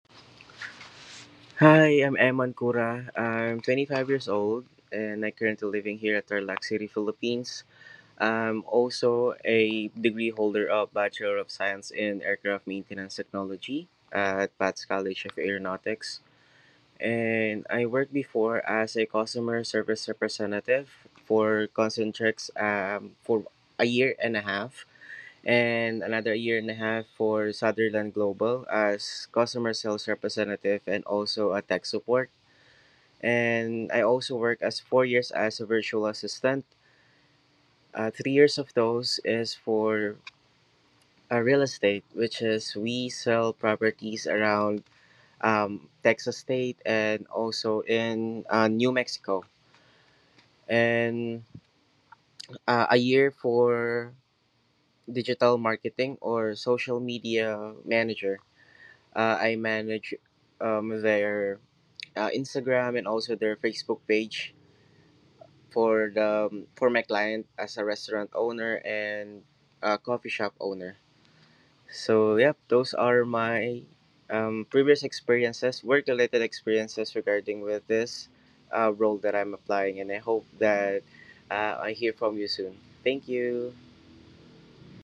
Self Introduction